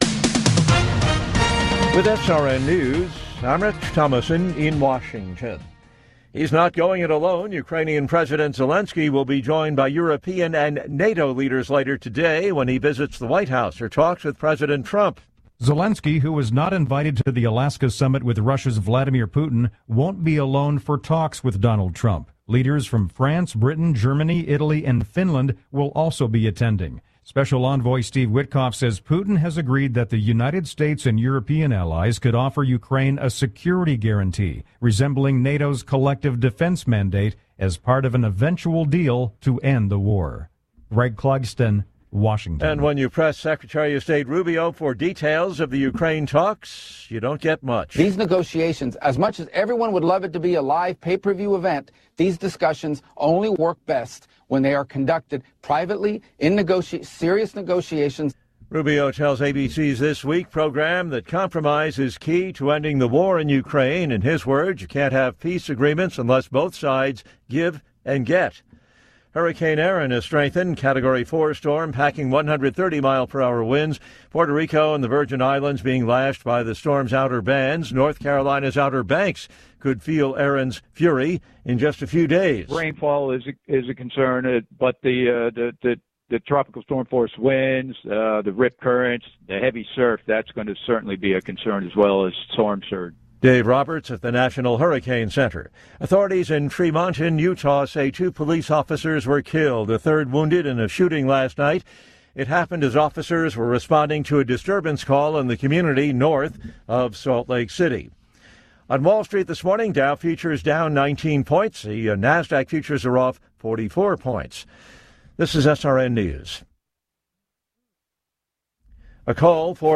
Top News Stories Aug 18, 2025 – 08:00 AM CDT